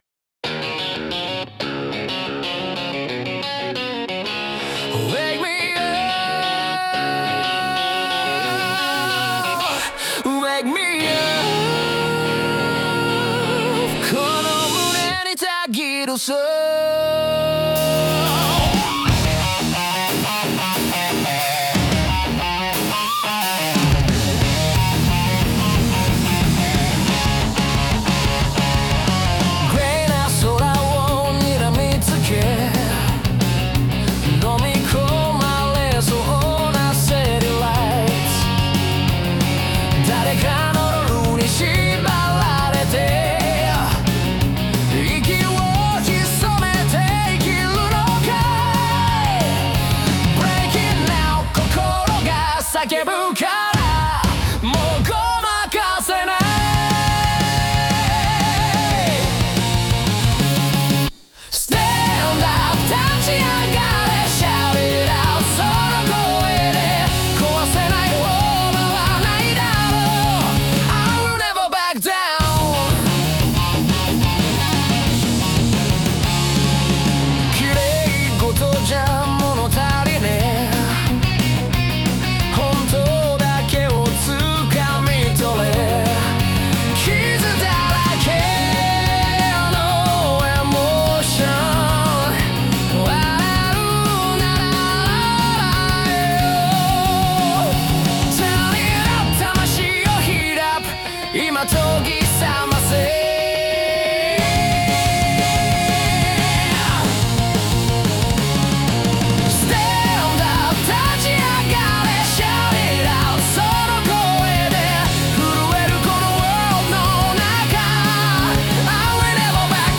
イメージ：邦ロック,ハードロック,ラウドロック,男性ボーカル,かっこいい,180BPM,アンセム